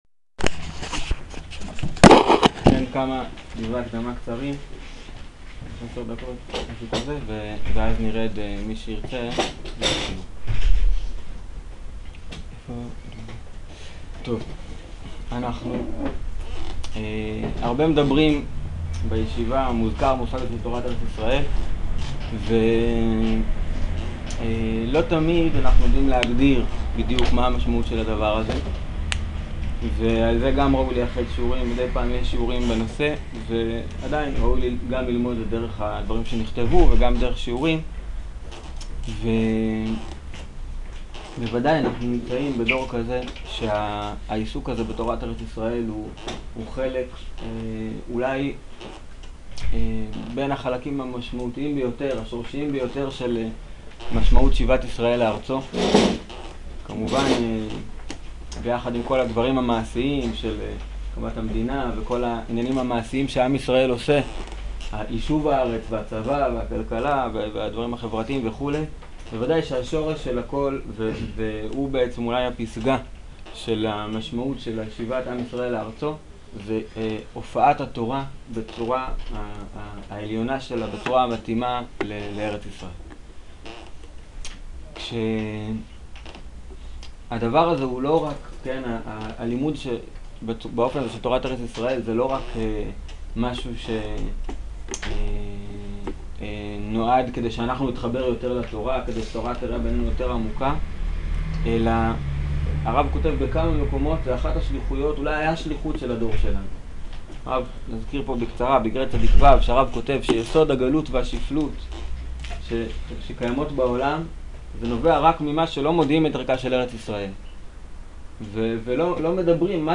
שיעור פתיחה לתוכנית לימוד במיטב הארץ